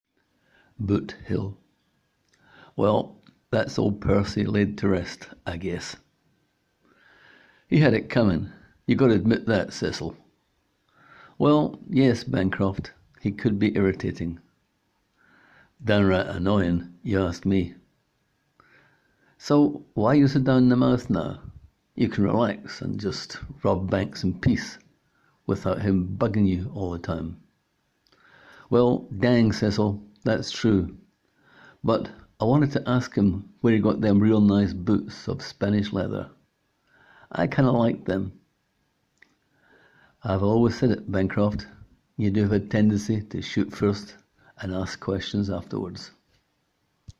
Click here to hear the writer read his words: